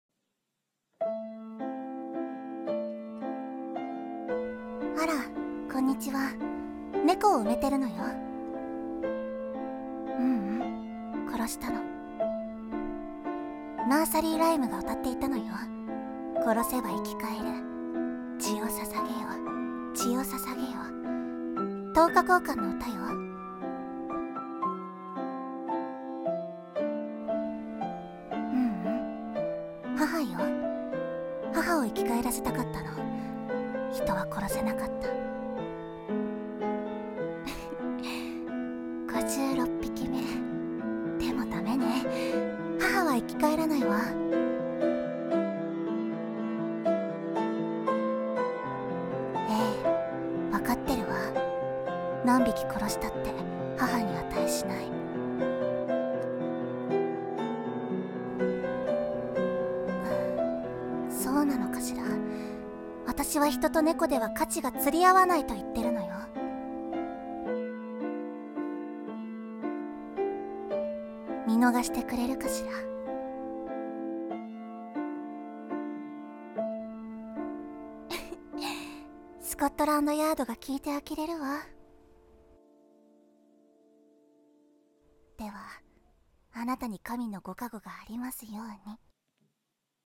等価交換【２人声劇】